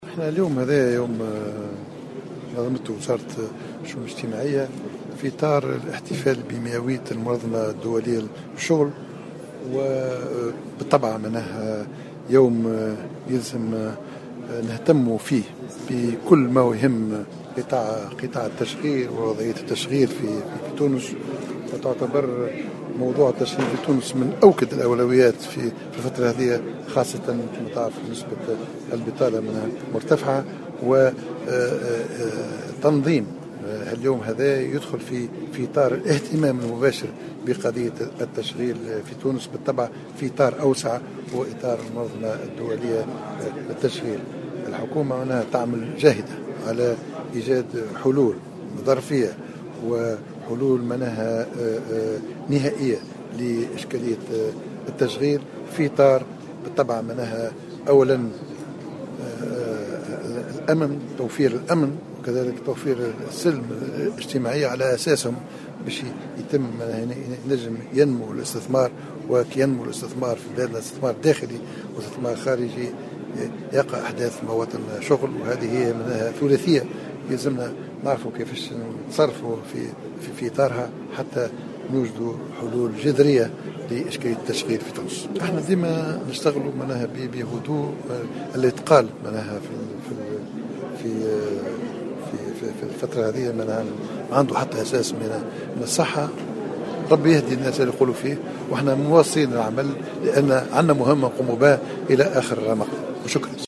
Dans une déclaration accordée à Jawhara Fm, Habib Essid a nié les rumeurs concernant sa suspension ou son intention de démissionner de son poste.